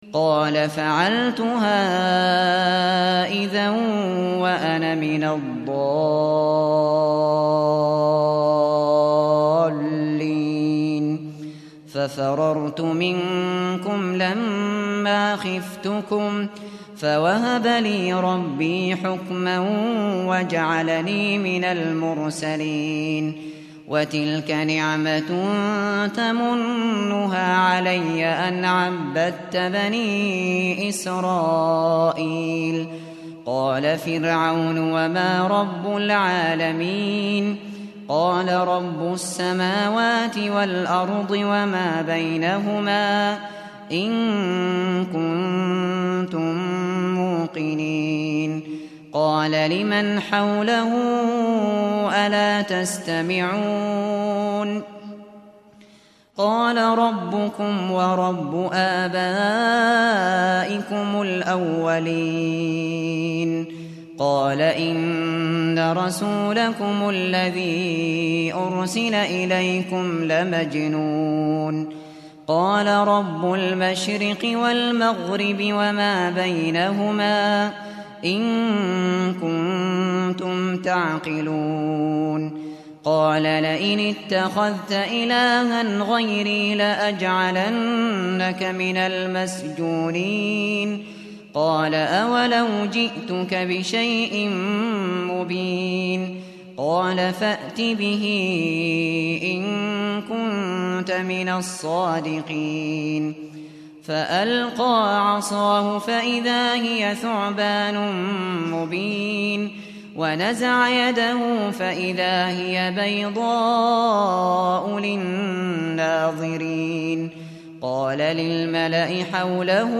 Kur'ân dinlemeye başlamak için bir Hafız seçiniz.